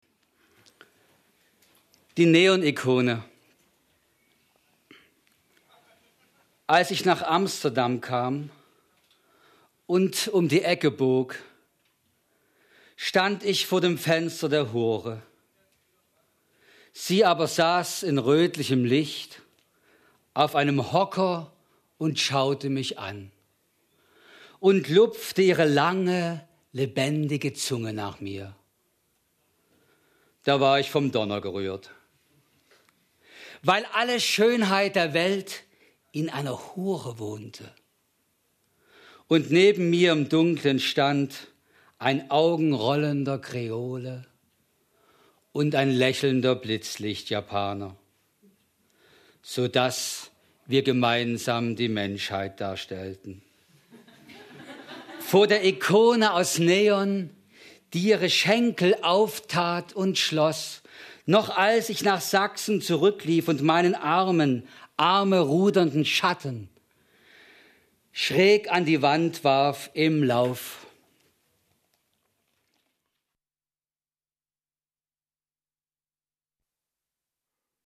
Lesung von Thomas Rosenlöcher in der literaturWERKstatt Berlin zur Sommernacht der Lyrik – Gedichte von heute